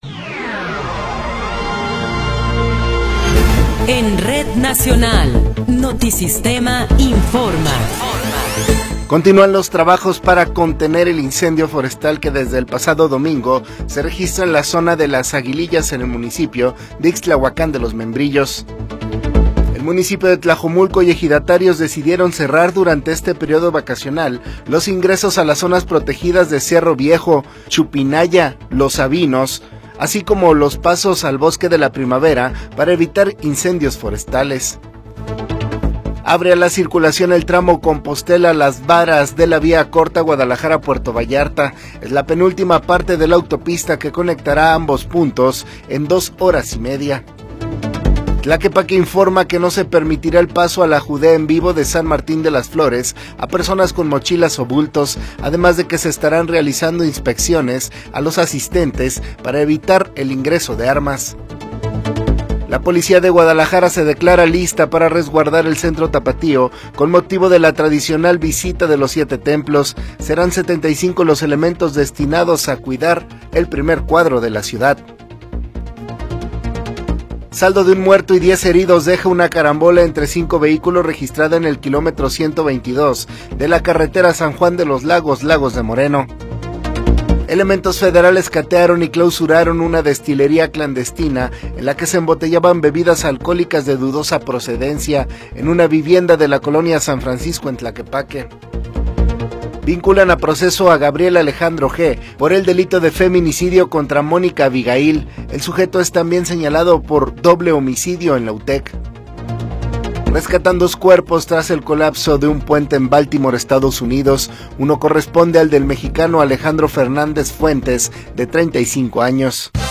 Noticiero 21 hrs. – 27 de Marzo de 2024
Resumen informativo Notisistema, la mejor y más completa información cada hora en la hora.